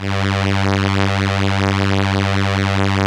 Index of /90_sSampleCDs/Keyboards of The 60's and 70's - CD1/STR_Elka Strings/STR_Elka Violins
STR_ElkaVlsG_3.wav